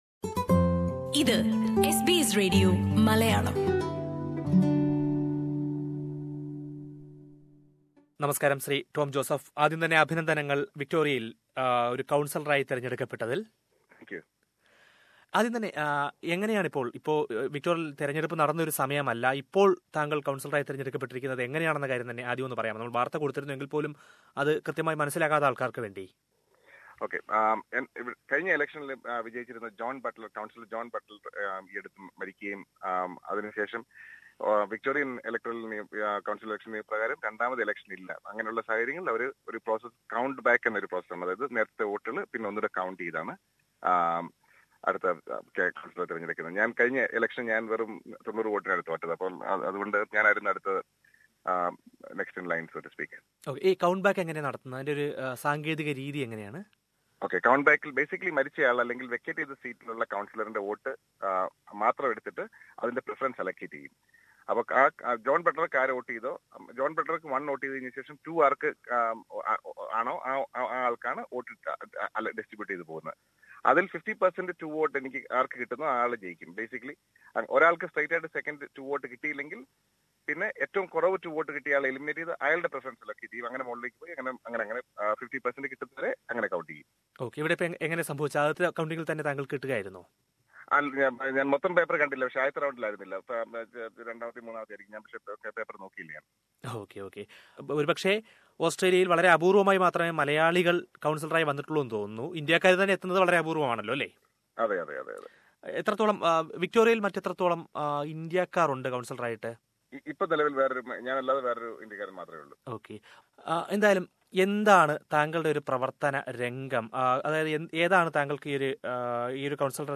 വിക്ടോറിയയില്‍ ആദ്യമായി ഒരു മലയാളി കൗണ്‍സിലര്‍ സ്ഥാനത്തേക്ക് തെരഞ്ഞെടുക്കപ്പെട്ടിരിക്കുകയാണ്. മെൽബണിലെ വിറ്റൽസി കൗൺസിലിലെ നോർത്ത് വാർഡിലാണ് മലയാളിയായ ടോം ജോസഫ് തെരഞ്ഞെടുക്കപ്പെട്ടത്. തെരഞ്ഞെടുപ്പ് സമയം അല്ലാതിരുന്നിട്ടും കൗണ്‍സിലറായി തെരഞ്ഞെടുക്കപ്പെട്ടതിനെക്കുറിച്ചും, പ്രവര്‍ത്തനമേഖലയെക്കുറിച്ചുമെല്ലാം ടോം ജോസഫ് എസ് ബി എസ് മലയാളം റേഡിയോയോട് സംസാരിക്കുന്നു.